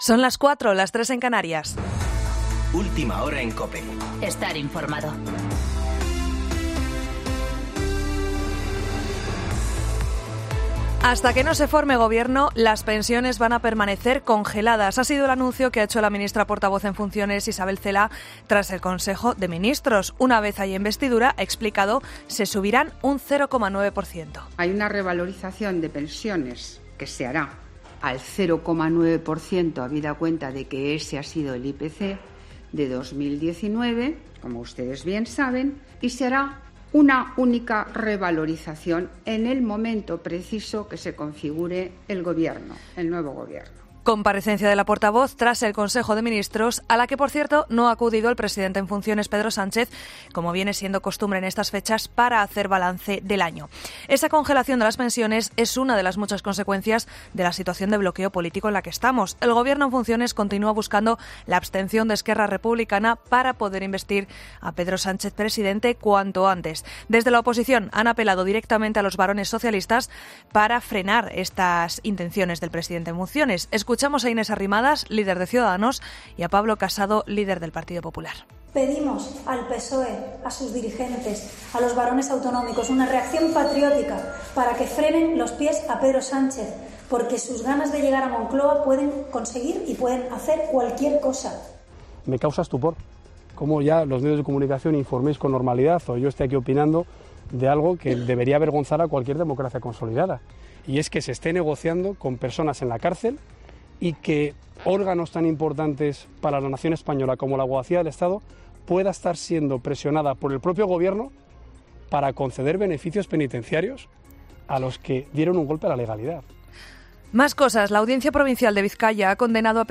Boletín informativo de COPE del 28 de diciembre de 2019 a las 4.00 horas